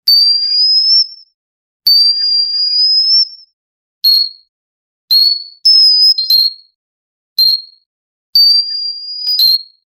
cartoon scurry spring pop or boing sparkle twinkle tiny laughter (optional)
cartoon-scurryspring-pop--7gutn5tj.wav